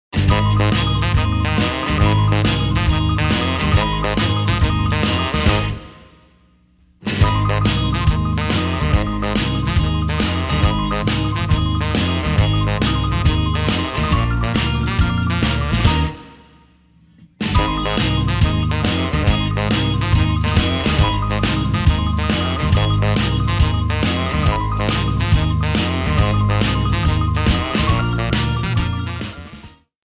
Ab
MPEG 1 Layer 3 (Stereo)
Backing track Karaoke
Pop, Oldies, 1960s